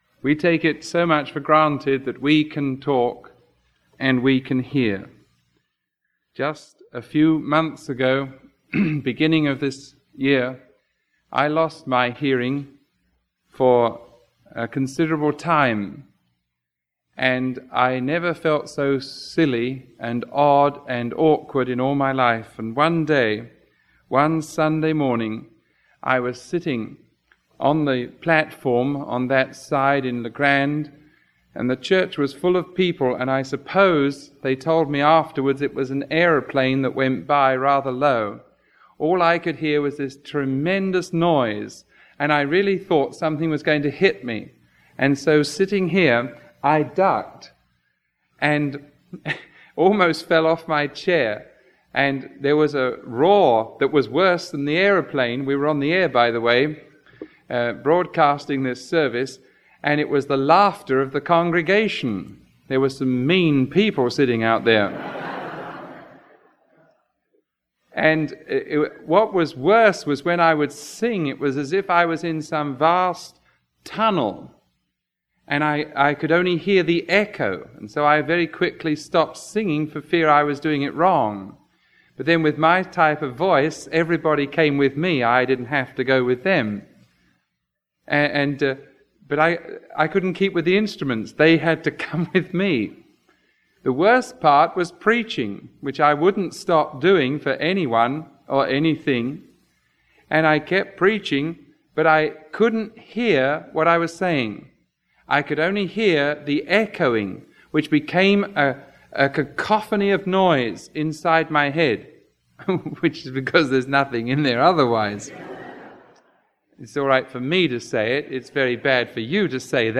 Sermon 0015B recorded on December 31